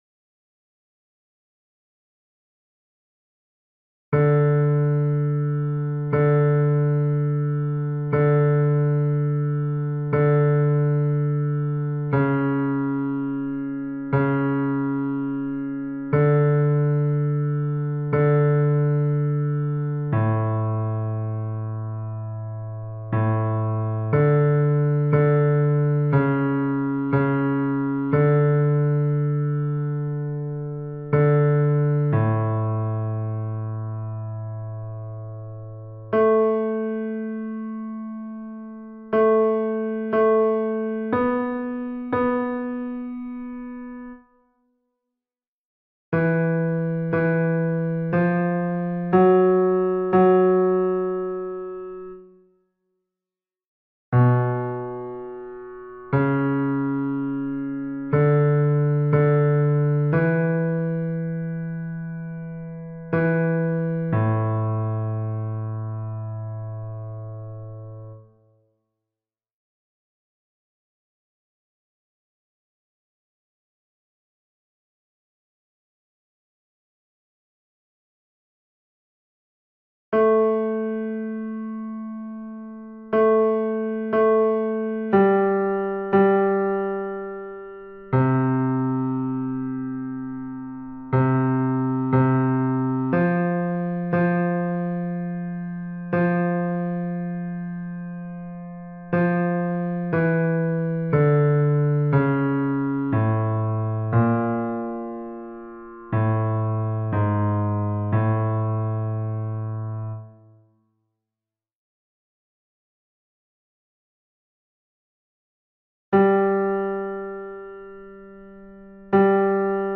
MP3 versions chantées
Basse (piano)
Ave Verum Corpus Mozart Basse Mp 3